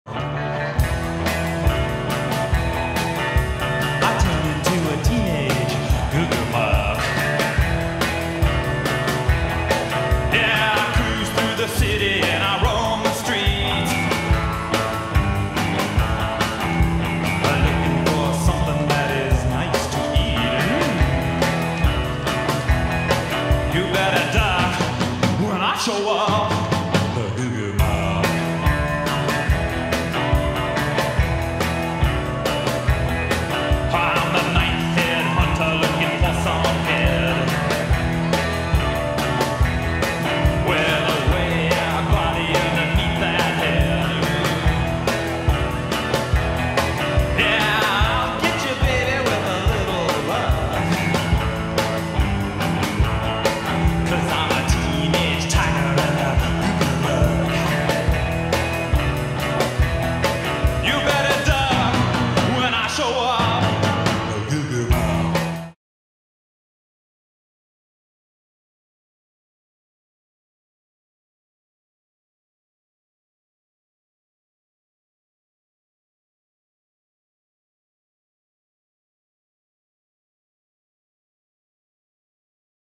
dance music tik tok